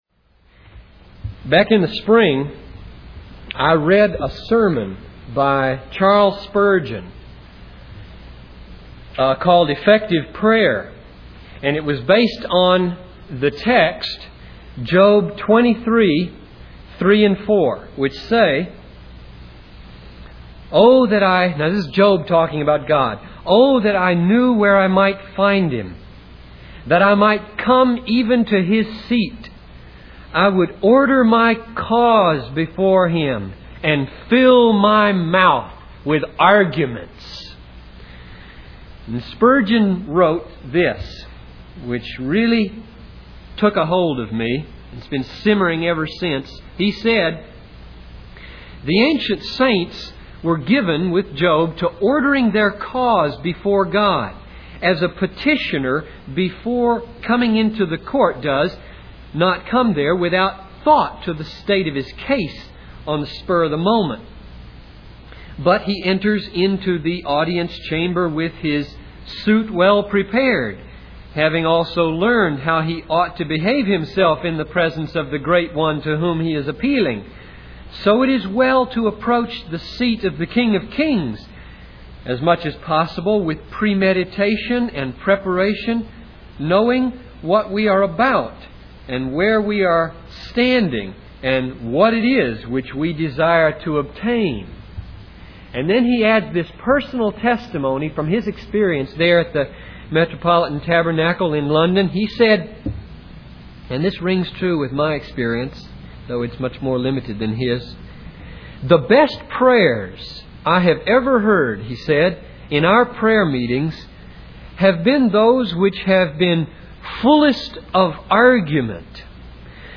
In this sermon, the speaker focuses on Psalm 143 as an example of how to approach God with our arguments.